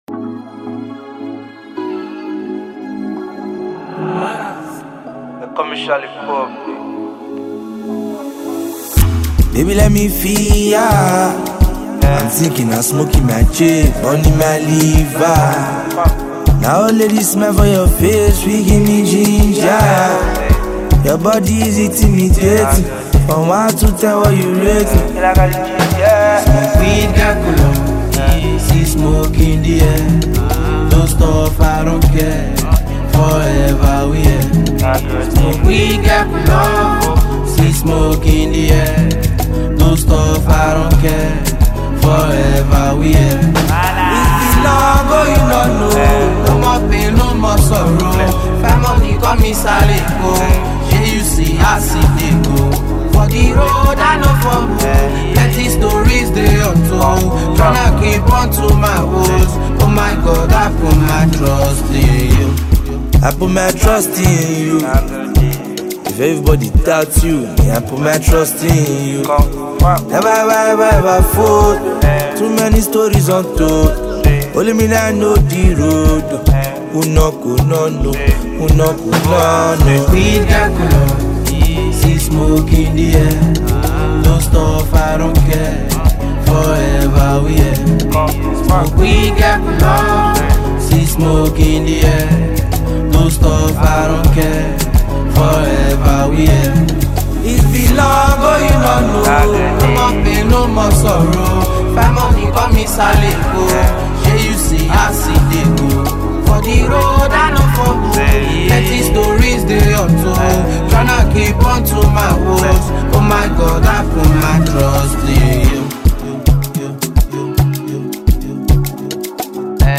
chill and melodic music